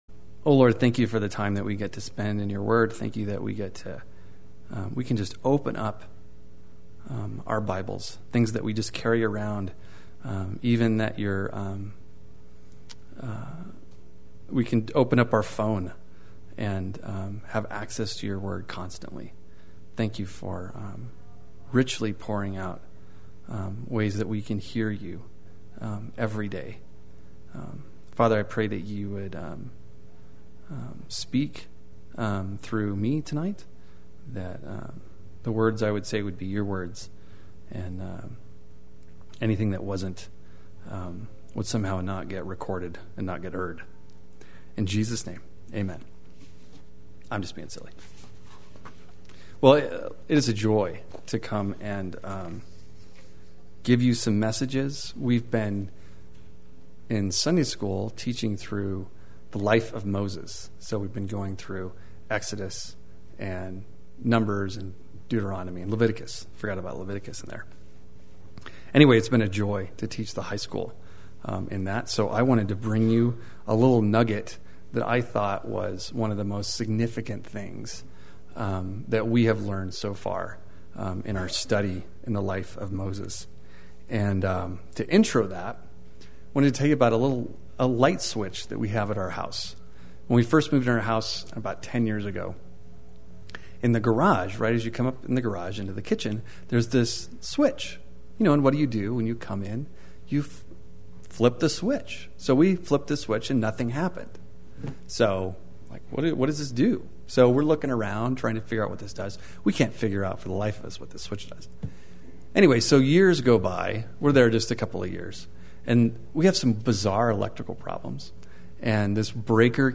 Play Sermon Get HCF Teaching Automatically.
Cosmic Treason Via the Golden Calf Wednesday Worship